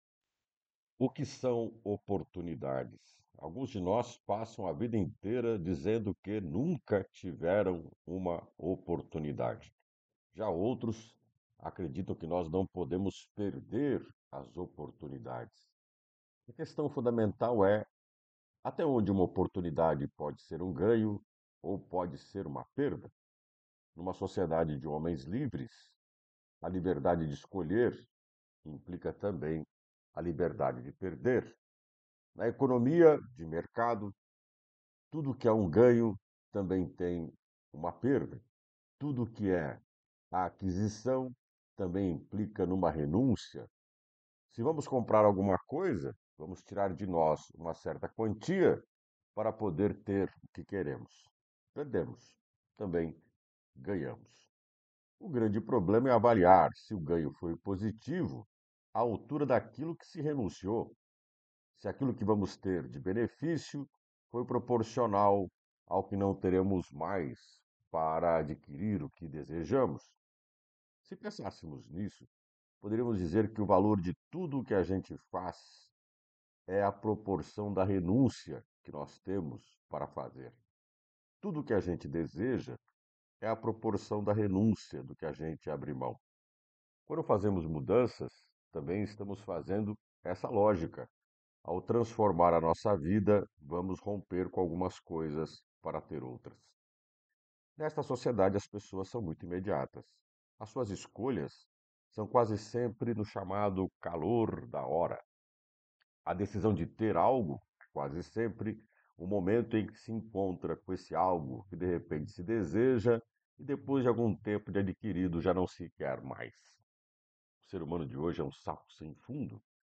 O comentário